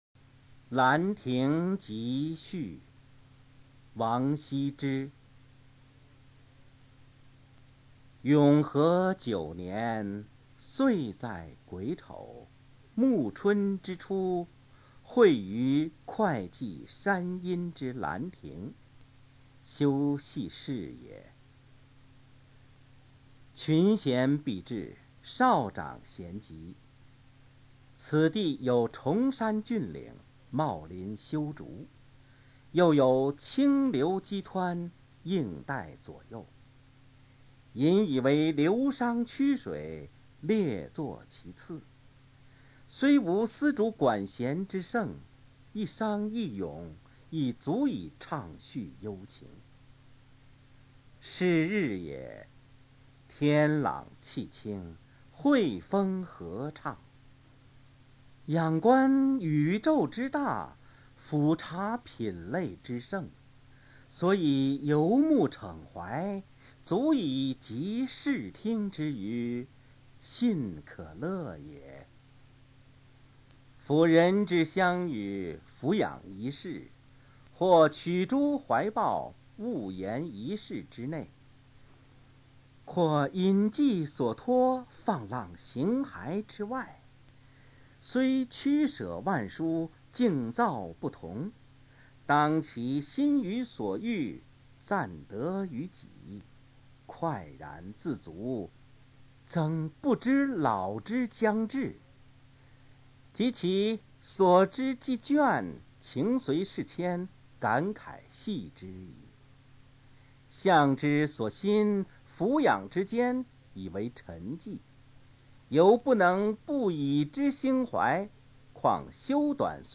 首页 视听 语文教材文言诗文翻译与朗诵 高中语文必修二
王羲之《兰亭集序》原文及译文（含朗读）